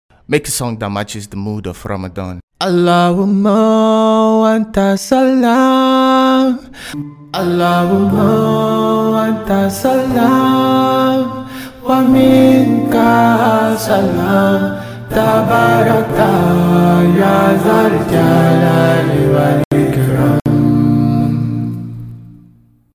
Nasheed , Vocals Only.